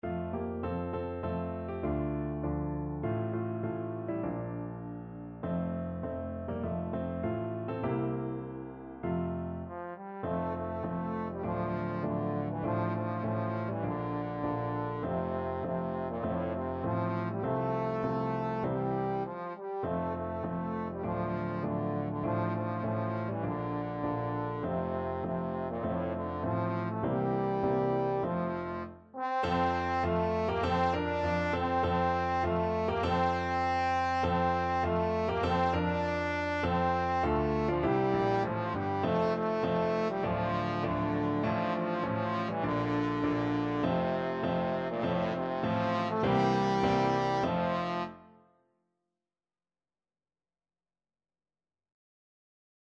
F major (Sounding Pitch) (View more F major Music for Trombone )
Moderato
Trombone  (View more Easy Trombone Music)
Traditional (View more Traditional Trombone Music)